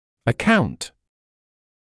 [ə’kaunt][э’каунт]счет; отчет, доклад; мнение; объяснять (что-л.), быть причиной, вызывать (что-л.)